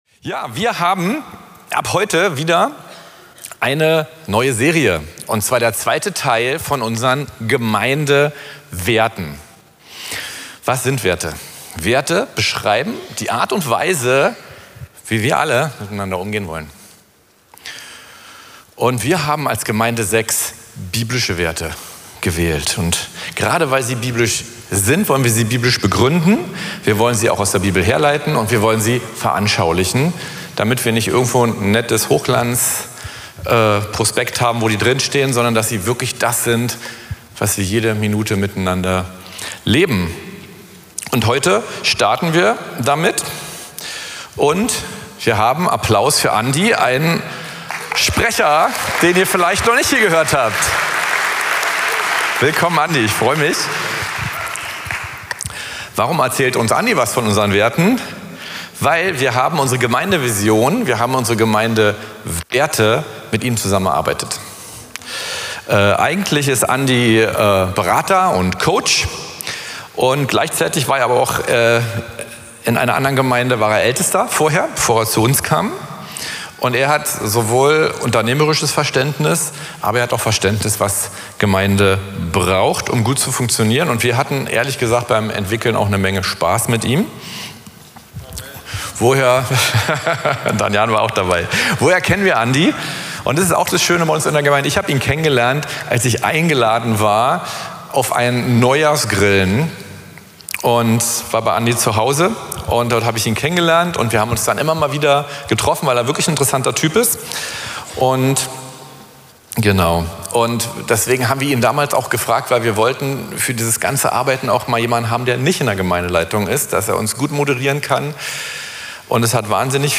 Predigten von Veranstaltungen der Gemeinde auf dem Weg, Berlin